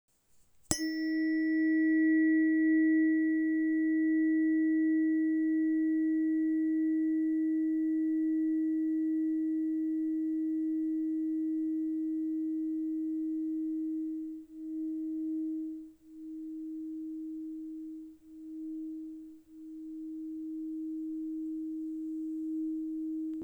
Terapeutická ladička 315,8 Hz Mozek hliník CZ
Její čistý, klidný tón jemně harmonizuje mozkové hemisféry, napomáhá duševní rovnováze a podporuje stav vnitřní jasnosti.
• Materiál: hliník
• Frekvence: 315,8 Hz (E♭)
• Ručně laděná s dlouhým, čistým dozvukem.